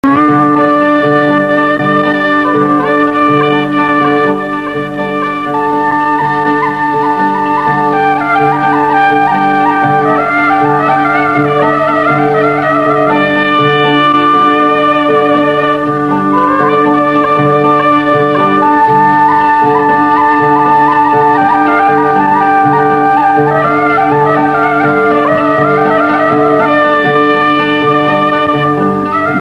flojt.mp3